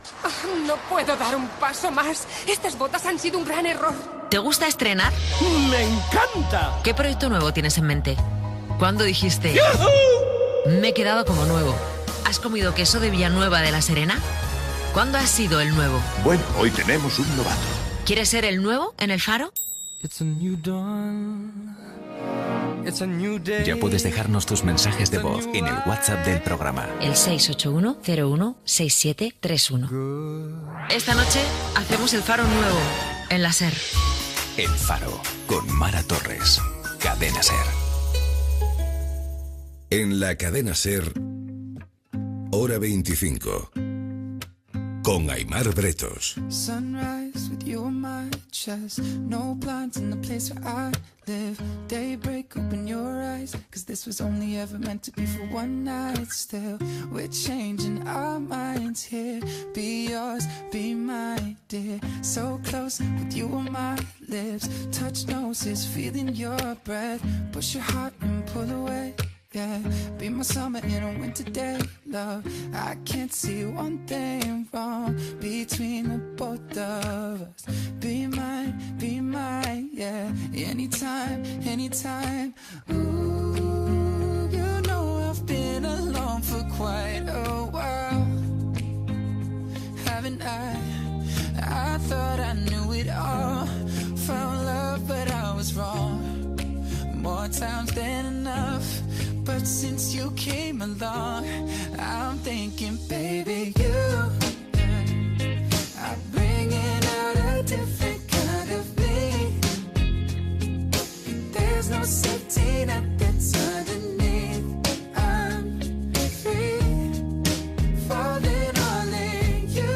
a0ef4214b2f9ab1f559cc5b0edfd324573d29c74.mp3 Títol Cadena SER Emissora Ràdio Barcelona Cadena SER Titularitat Privada estatal Nom programa Hora 25 Descripció Publicitat, promoció del programa "El faro", indicatiu del programa, tema musical, entrevista a la periodista Pepa Bueno, directora del diari "El País". S'hi parla de l'increment de lectors del diari i del seu finançament basat en la subscrició